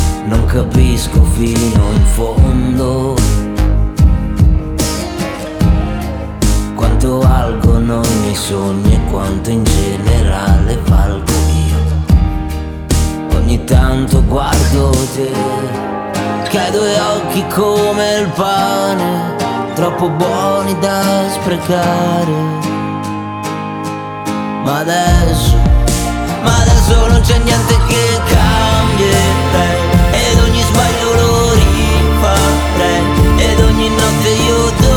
Жанр: Поп